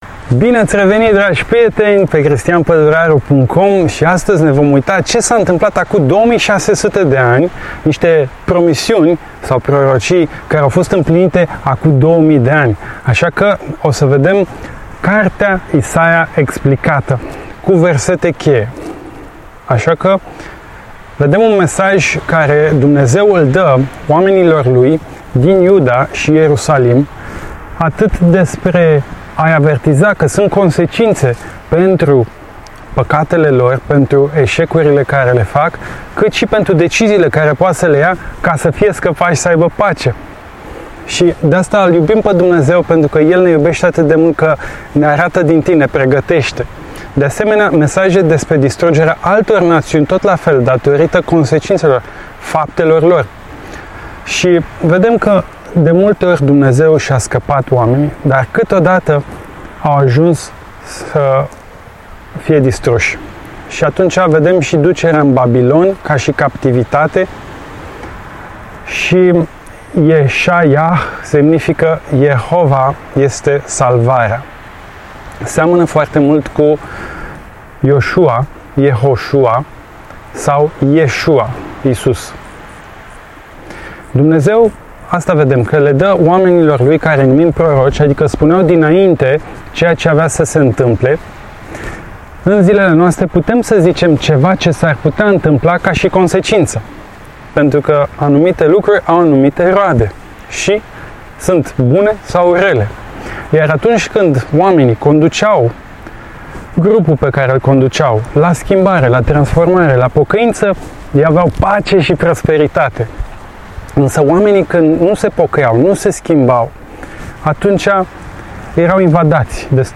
Predica Isaia